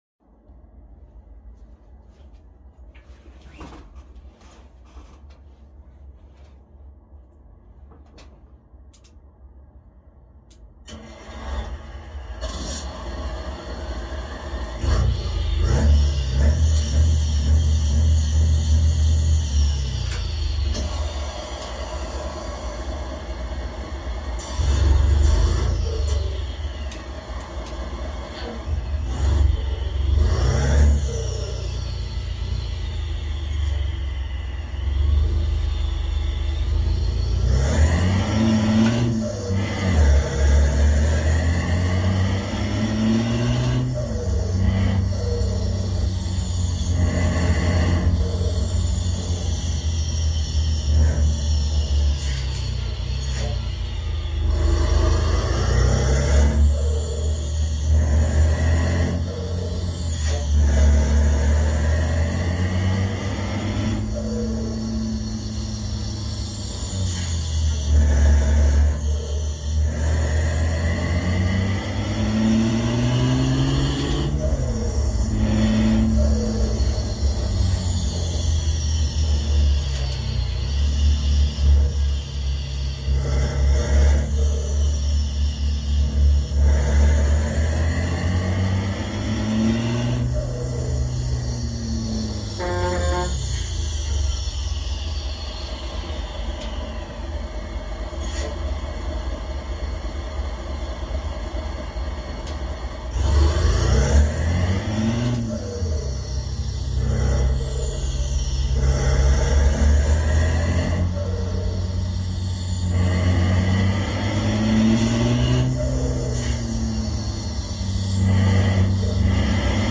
EuroTruckSimulator2 Long Gameplay/All links to sound effects free download